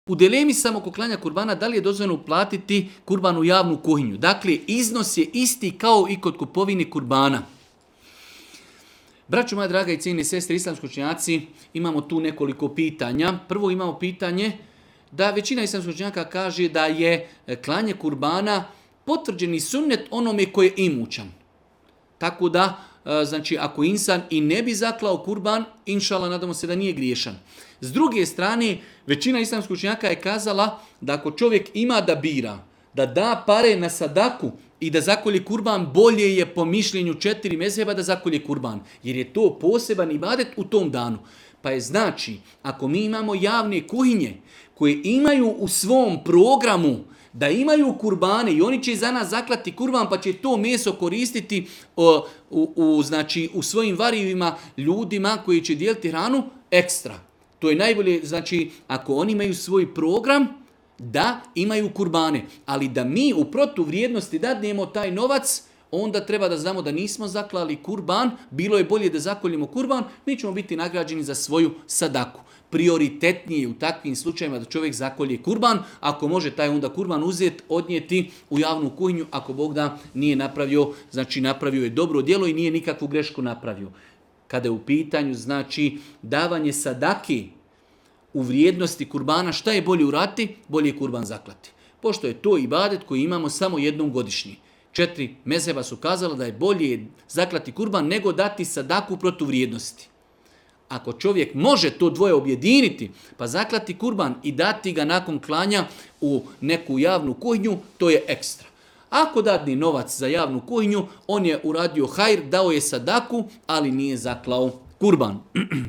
u video predavanju ispod